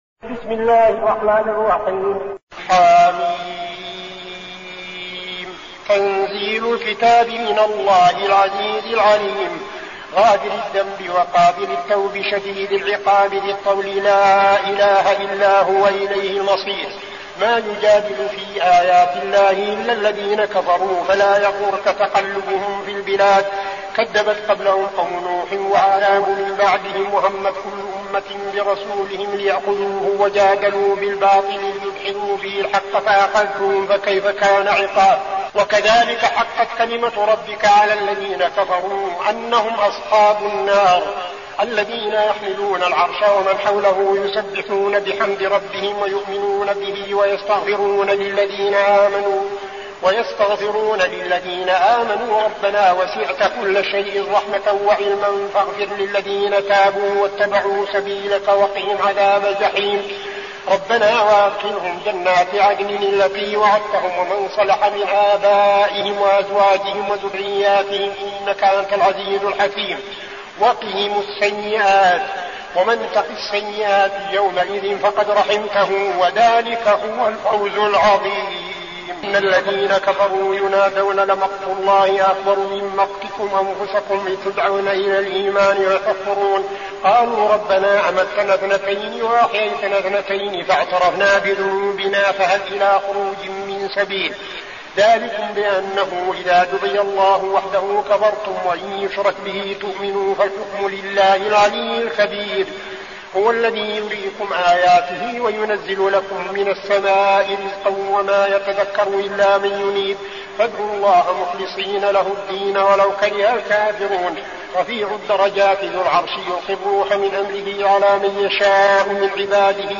المكان: المسجد النبوي الشيخ: فضيلة الشيخ عبدالعزيز بن صالح فضيلة الشيخ عبدالعزيز بن صالح غافر